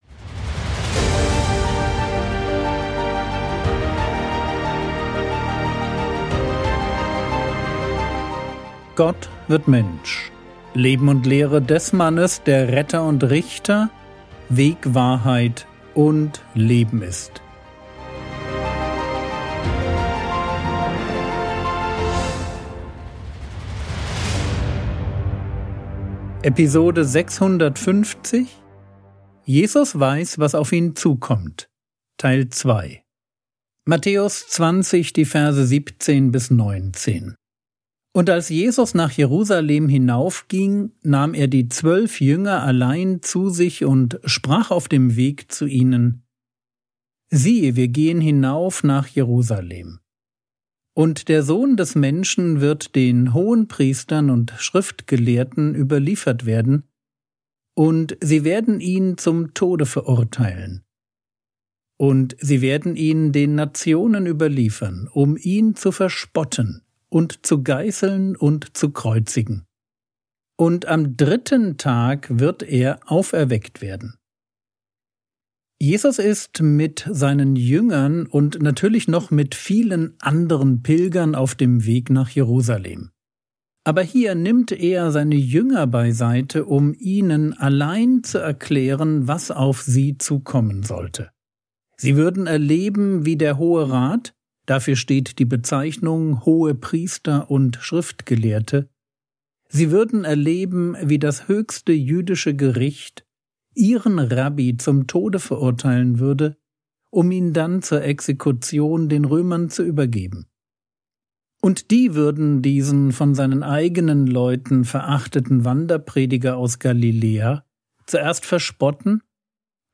Episode 650 | Jesu Leben und Lehre ~ Frogwords Mini-Predigt Podcast